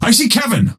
Dynamo voice line - I see Kevin!